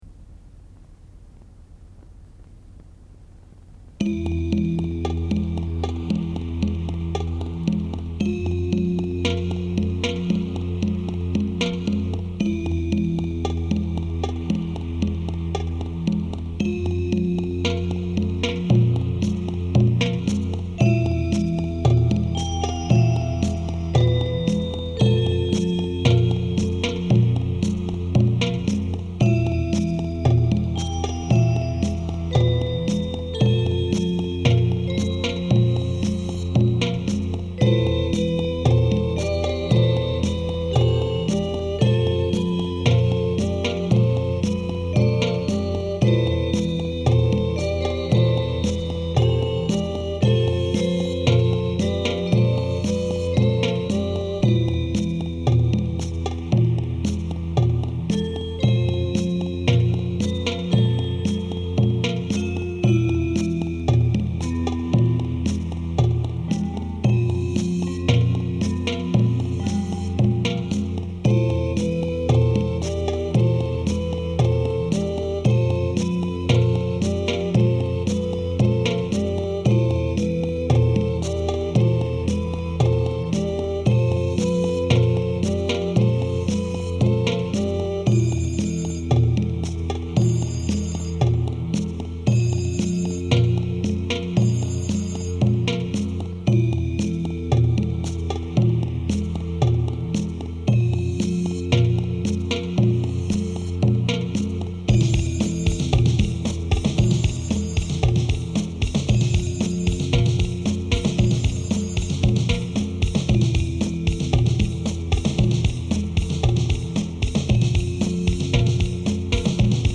Tags: nightmares dj mixes rock n roll